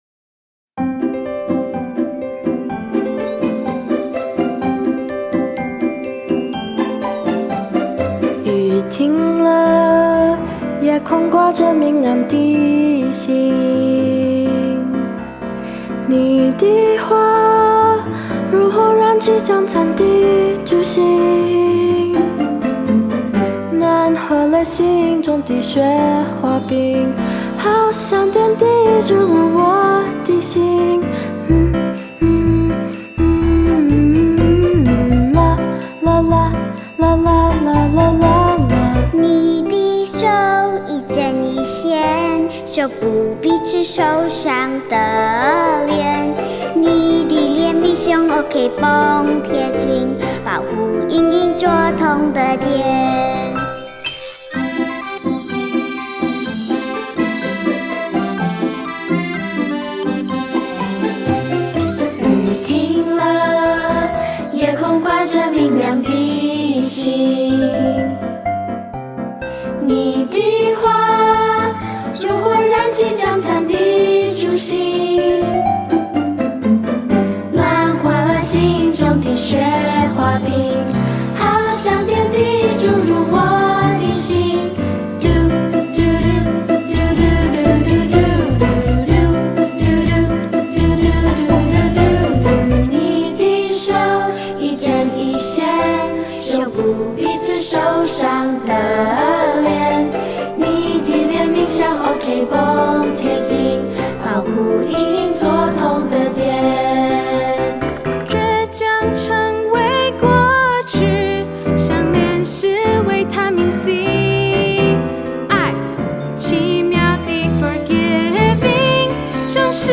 视频里有动作演示，音频里歌会自动重复三遍。